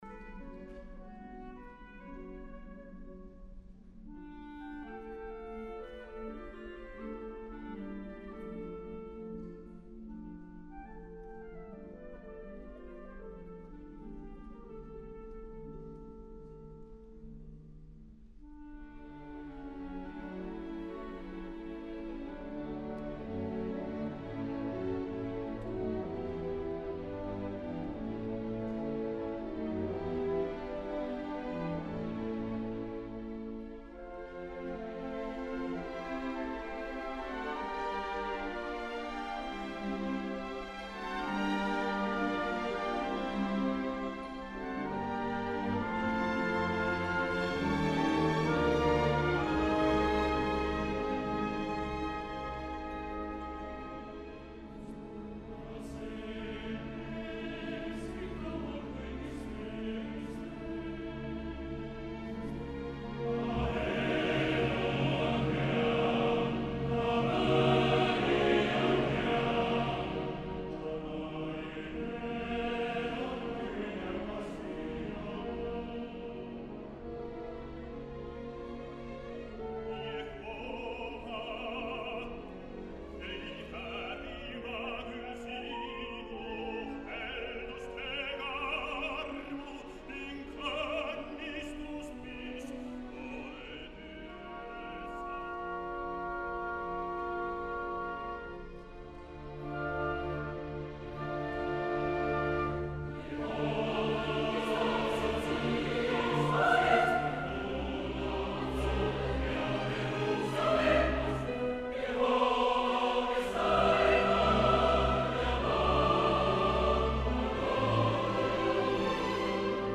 Oratorium
Live Recording: 16. August 1997 im Estonia Konzertsaal.